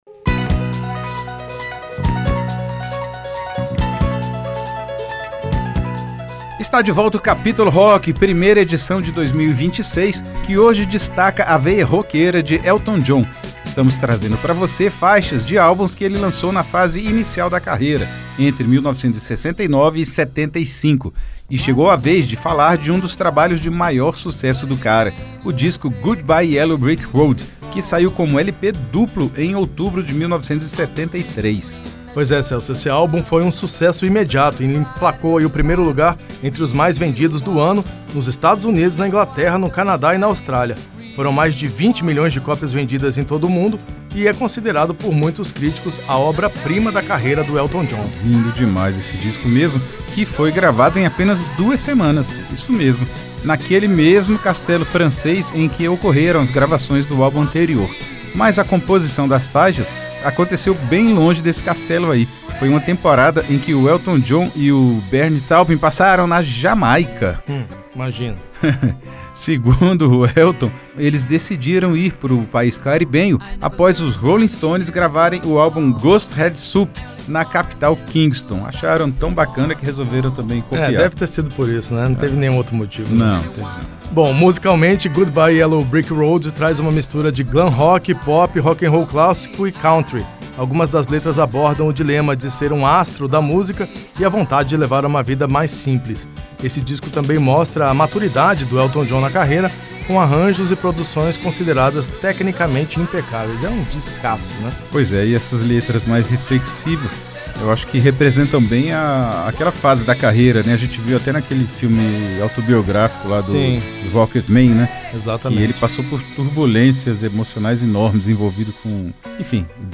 passeia pelo progressivo, o psicodélico e até o hard rock